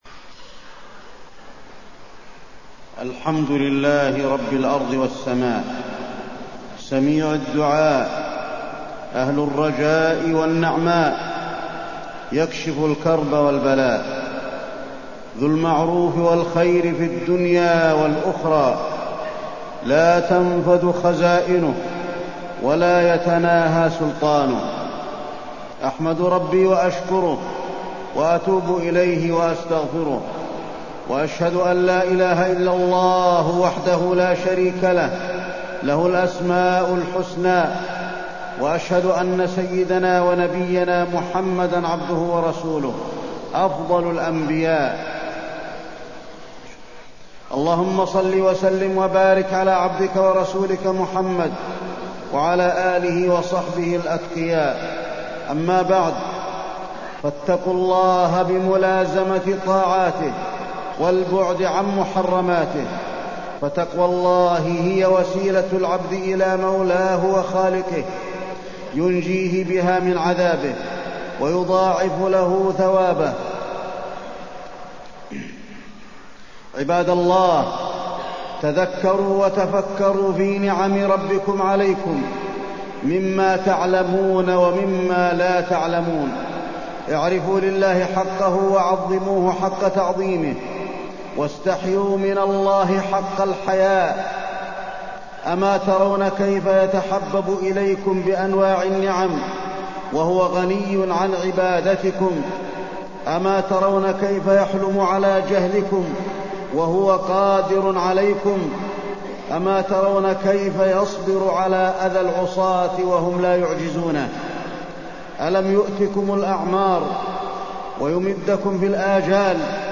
تاريخ النشر ٦ رمضان ١٤٢٤ هـ المكان: المسجد النبوي الشيخ: فضيلة الشيخ د. علي بن عبدالرحمن الحذيفي فضيلة الشيخ د. علي بن عبدالرحمن الحذيفي شهر رمضان